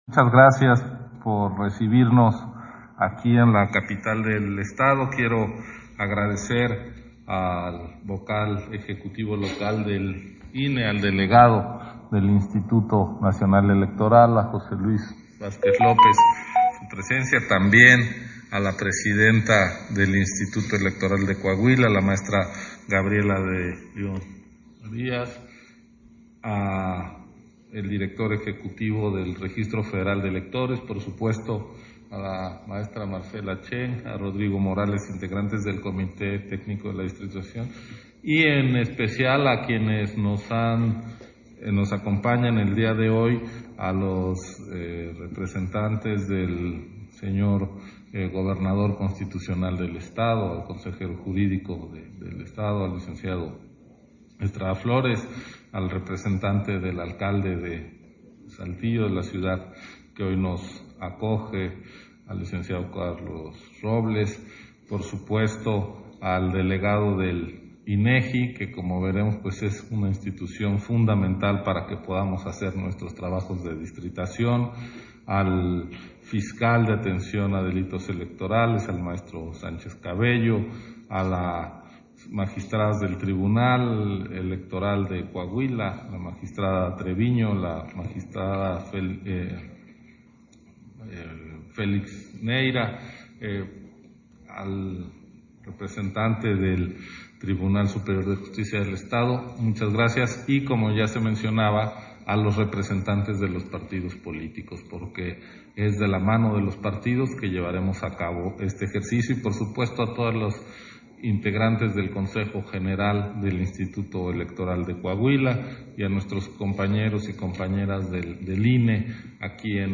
Intervención de Ciro Murayama, en el Foro Estatal de Distritación Nacional Electoral 2021-2023